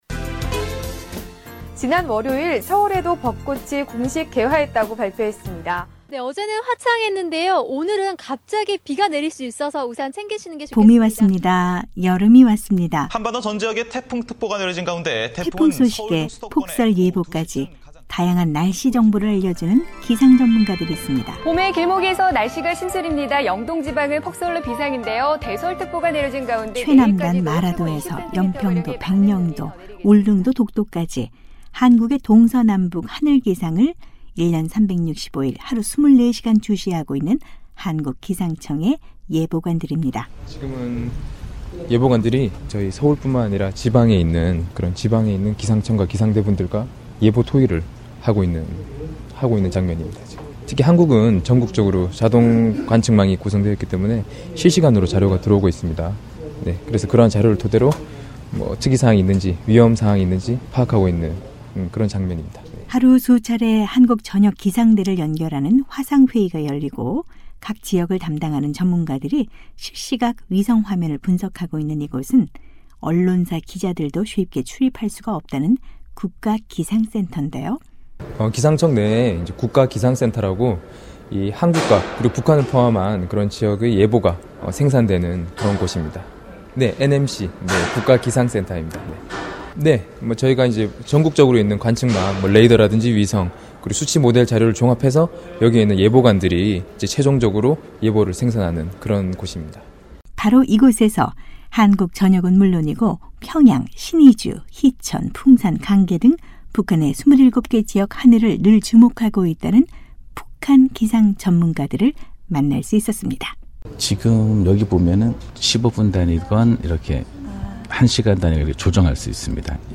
한반도 전역의 다양한 기상정보를 분석해 내는 한국 기상청 국가기상센터에서 북한 전문 예보관들을 만났습니다.